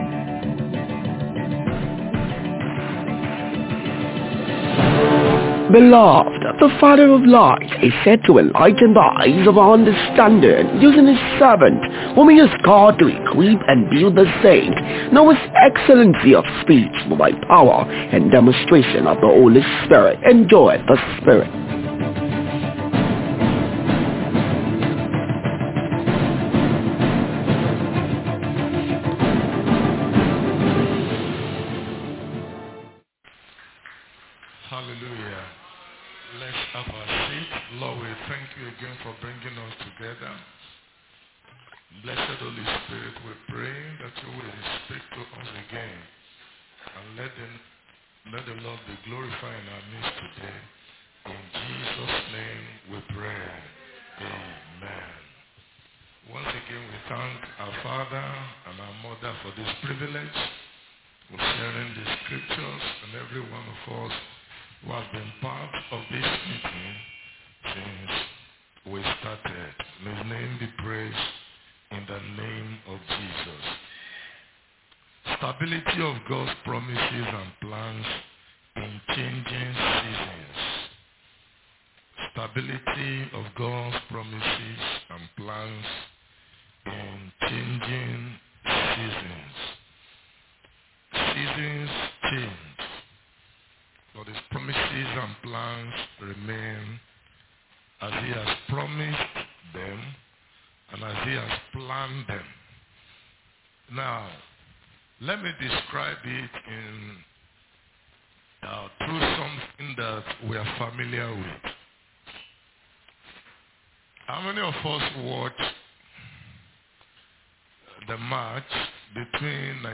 Youth Convention Day 2 Evening Message 1-Stability of God’s Promises and Plans in Changing Seasons
Youth-Convention-Day-2-Evening-Message-1-Stability-of-Gods-Promises-and-Plans-in-Changing-Seasons.mp3